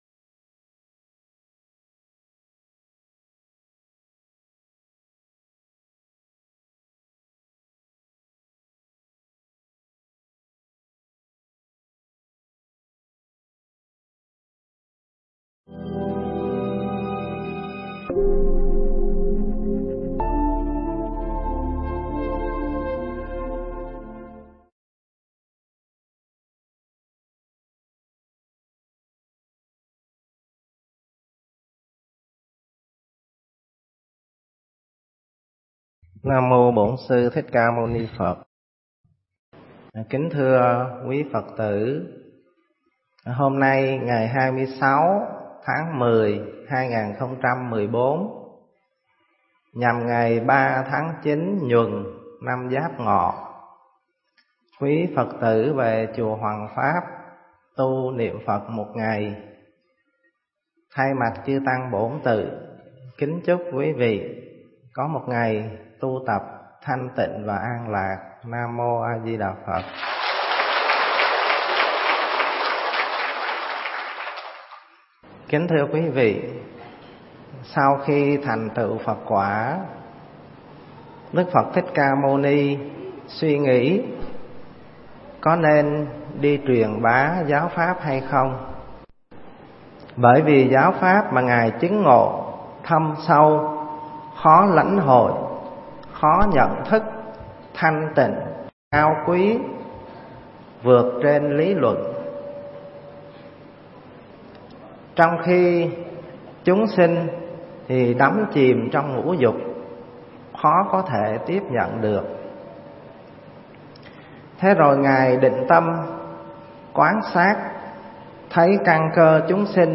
Nghe Mp3 thuyết pháp Như Voi Giữa Trận